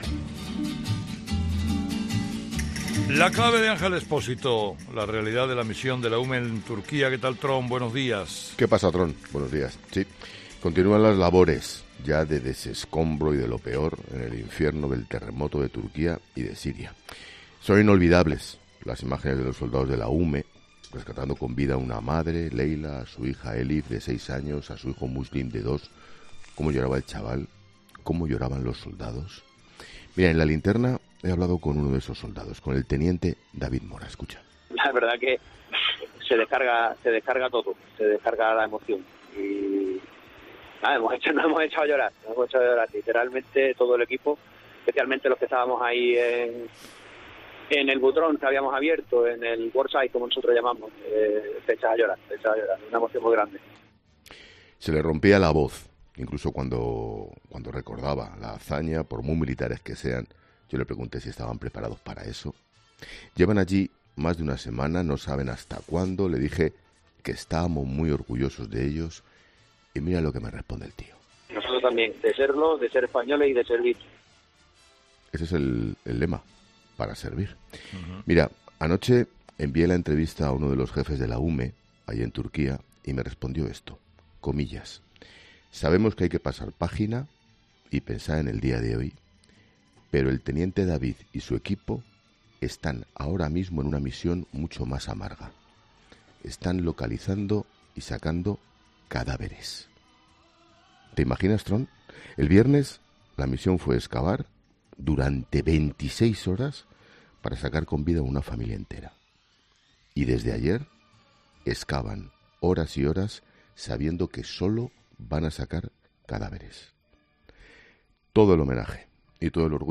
Expósito explica que desde ayer, los militares de la UME en Turquía ya buscan cadáveres